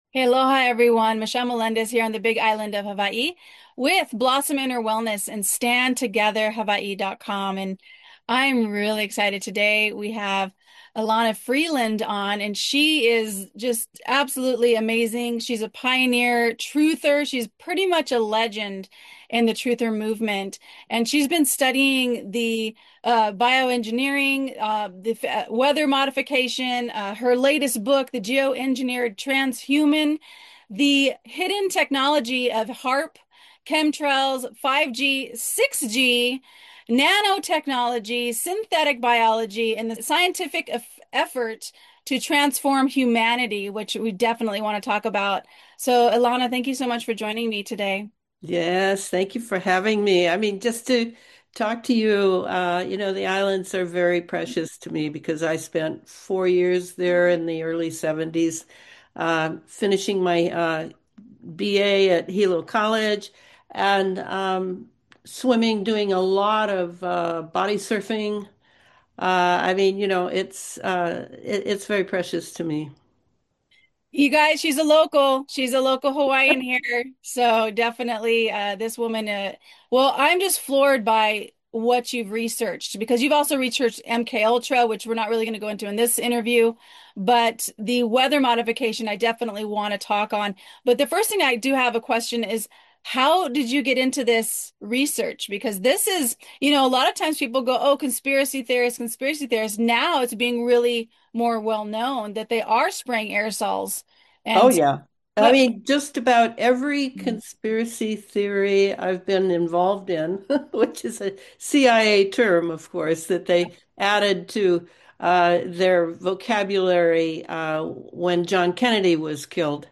The Gradual Takeover of Evil - Geoengineering Hidden Systems (interview